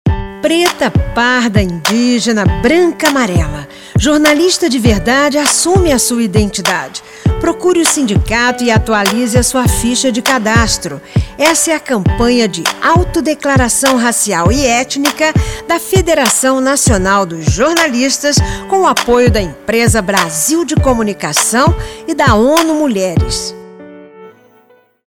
Spot de rádio da campanha